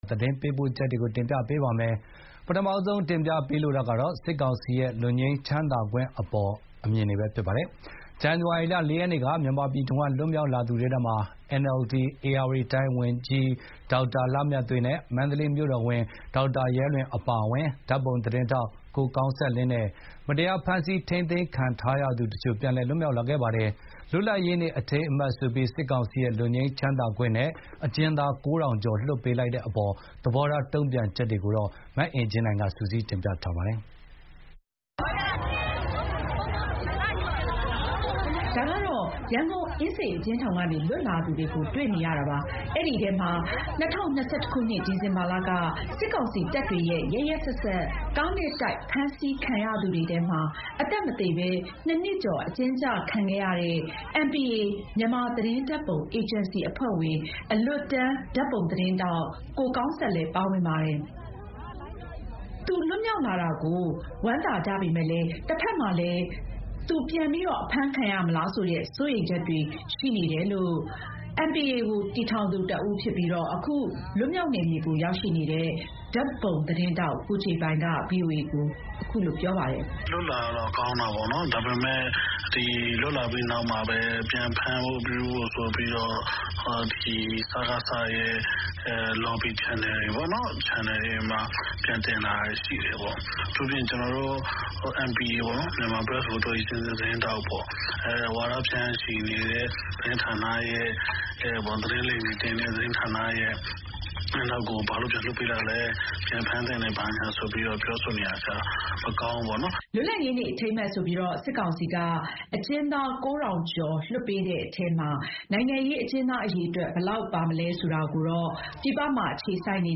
ဒီတခေါက်လွတ်လာသူတွေထဲမှာ NLD ပါတီမှာ အရေးပါတဲ့ တာဝန်တွေကို ထမ်းဆောင်နေသူတွေဖြစ်တဲ့ ဧရာဝတီတိုင်း လူမှုရေးဝန်ကြီး ဒေါက်တာလှမြတ်သွေး၊ မန္တလေး မြို့တော်ဝန် ဒေါက်တာရဲလွင်တို့ ပါဝင်တဲ့အပေါ်မှာ လွတ်မြောက်နယ်မြေကိုရောက်နေတဲ့ NLD ပြည်သူ့လွှတ်တော်ကိုယ်စားလှယ် ကိုစည်သူမောင်က သူ့အမြင်ကို အခုလို ဗွီအိုအေကိုပြောပါတယ်။